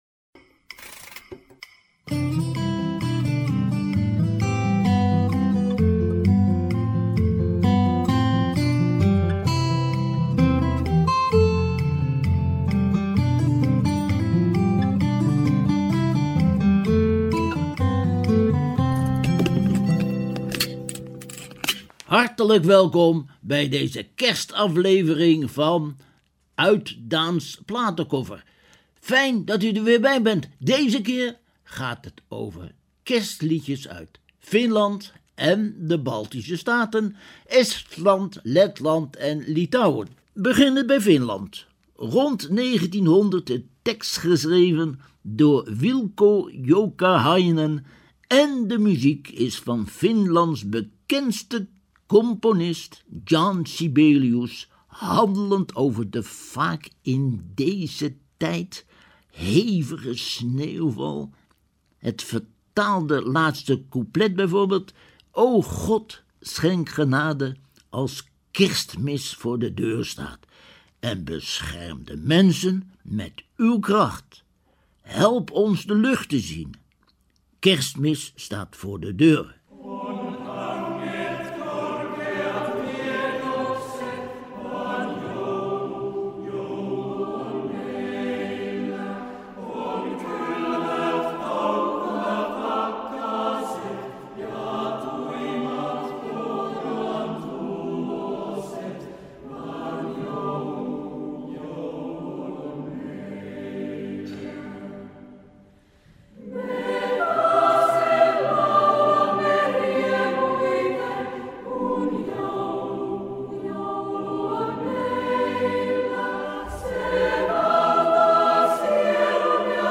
Zo goed als zeker is Radio Bloemendaal het enige zendstation dat kerstliederen gezongen in de daar gesproken talen van die landen uitzendt. Ook daar hoort u ze soms zingen op de melodieën die afkomstig zijn van de Verenigde Staten van Amerika en West-Europa.